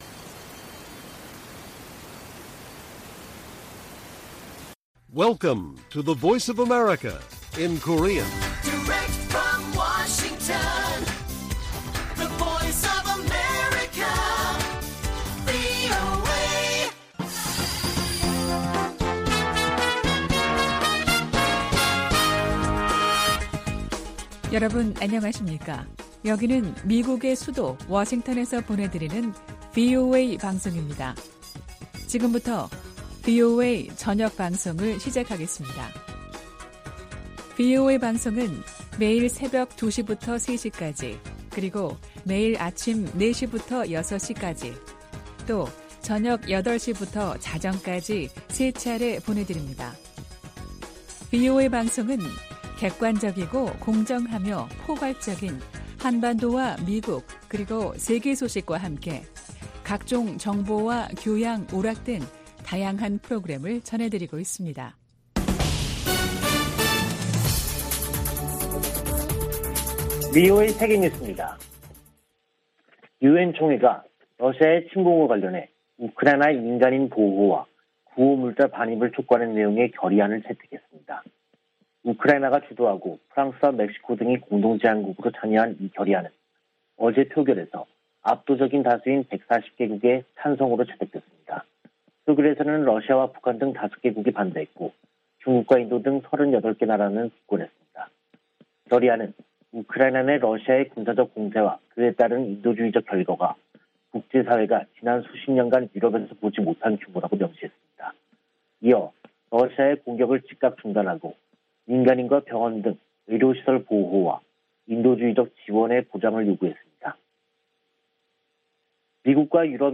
VOA 한국어 간판 뉴스 프로그램 '뉴스 투데이', 2022년 3월 25일 1부 방송입니다. 북한은 24일 발사한 탄도미사일이 신형 ICBM인 '화성-17형'이라며 시험발사에 성공했다고 밝혔습니다. 미국 정부는 북한이 ICBM으로 추정되는 장거리 탄도미사일을 발사한 데 강력한 규탄 입장을 밝혔습니다. 미국 정부가 북한 미사일 프로그램에 연관된 북한과 러시아의 개인과 회사들을 제재했습니다.